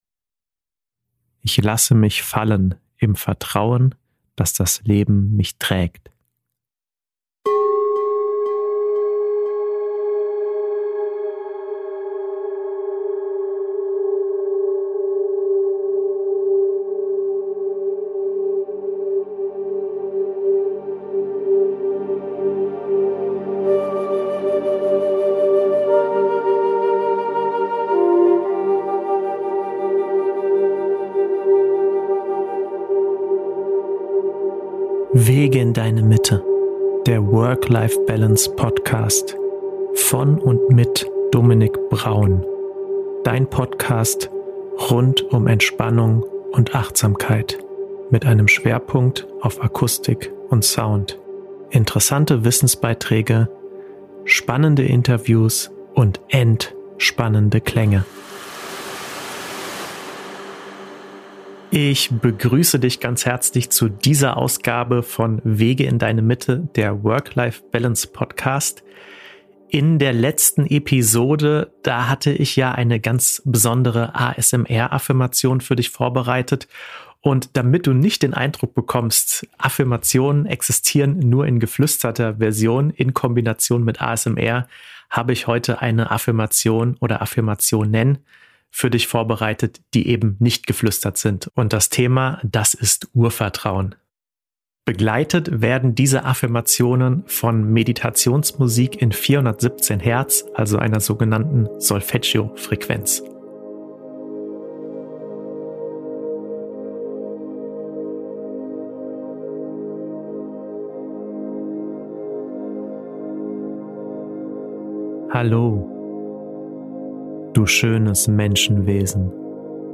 Allerdings präsentiere ich Dir heute keine ASMR-Affirmationen, sondern „normale“ ungeflüsterte Affirmationen. Thema: Urvertrauen.